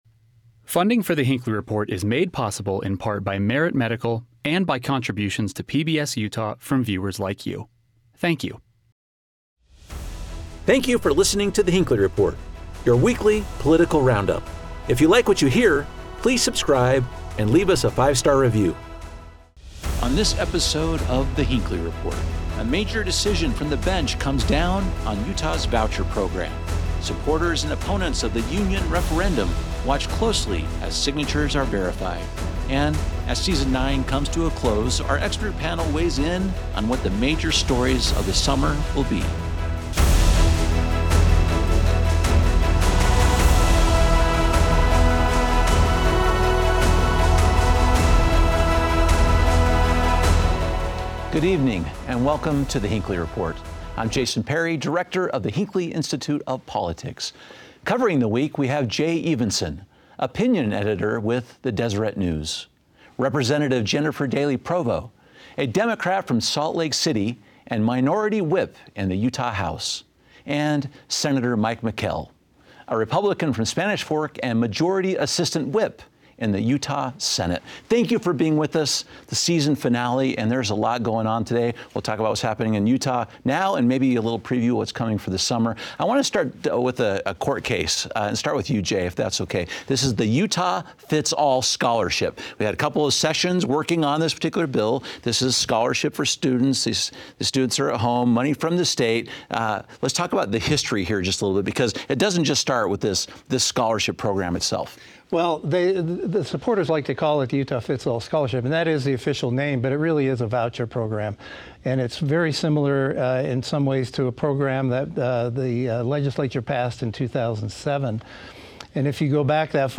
Our expert panel discusses the ruling, and what this means for the voucher program moving forward. Organizers of a referendum effort in Utah are inching closer to qualifying for the ballot.